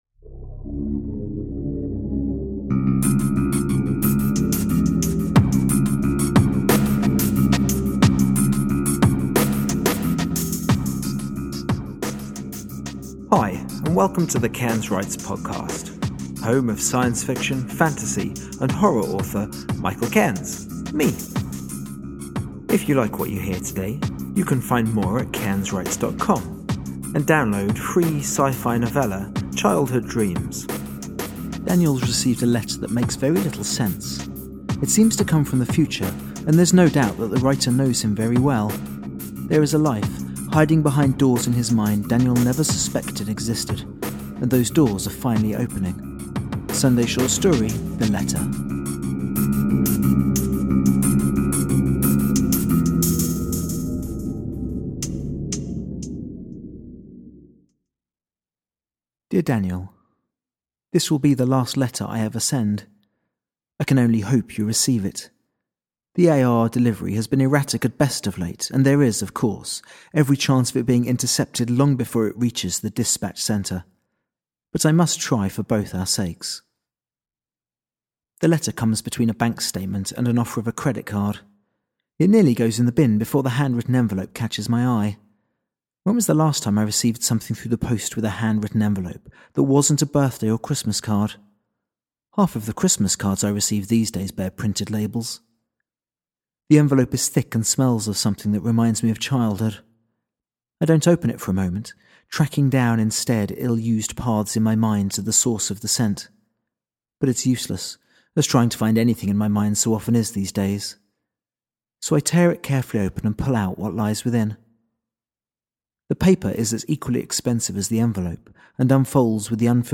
Every week I’ll be reading a short story or piece of serialised fiction.